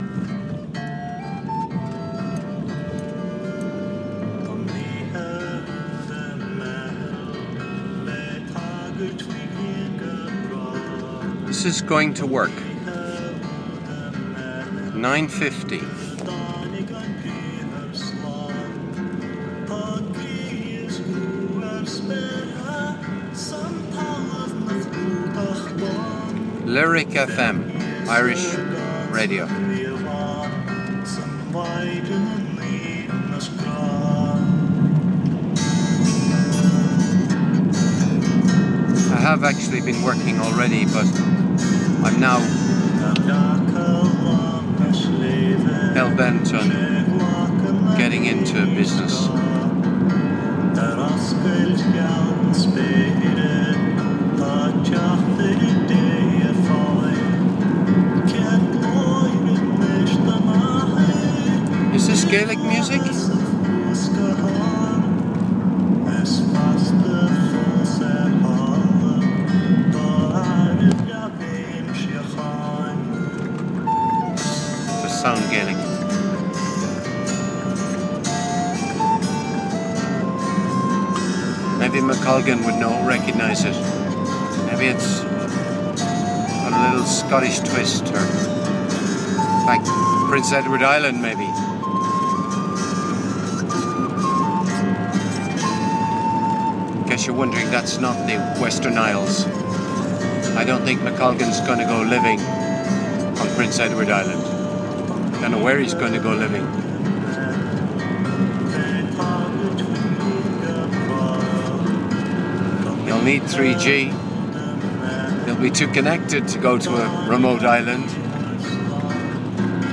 Music in the car